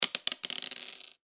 サイコロ・ダイス | 無料 BGM・効果音のフリー音源素材 | Springin’ Sound Stock
小さいダブルダイス1.mp3